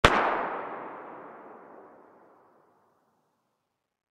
Index of /fastdl/sound/cracks/distant